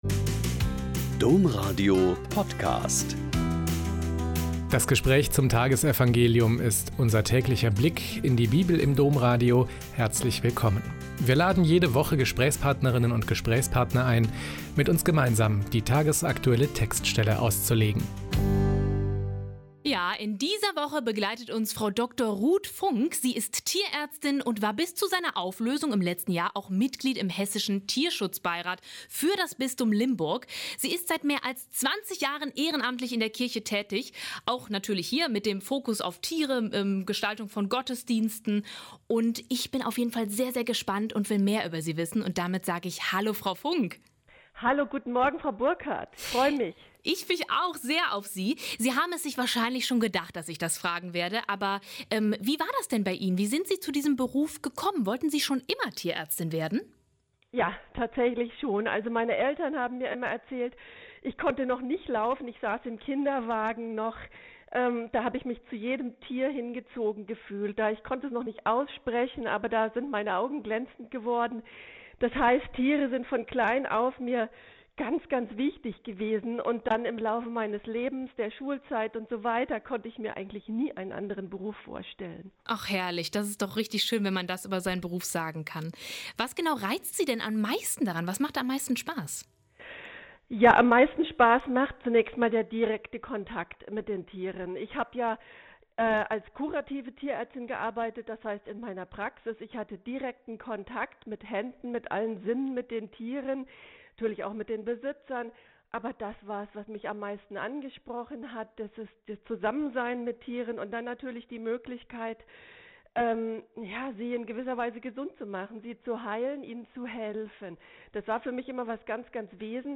Joh 1,43-51 - Gespräch